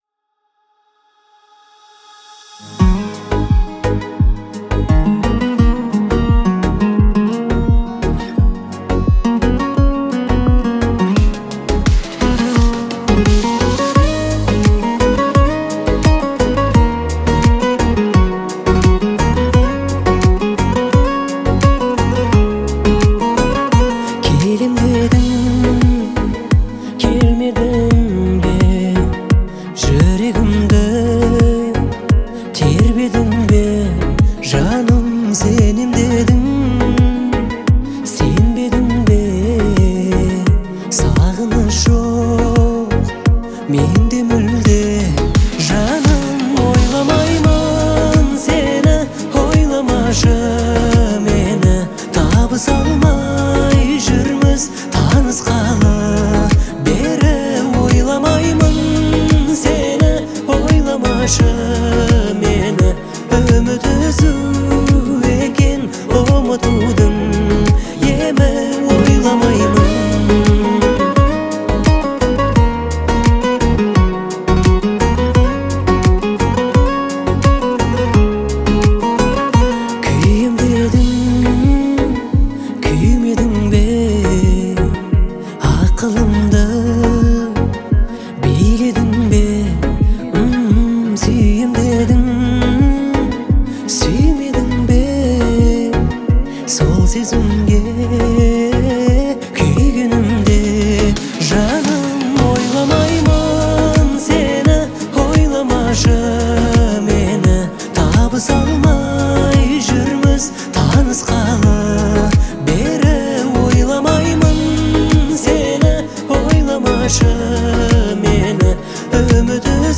трогательную балладу в жанре казахской поп-музыки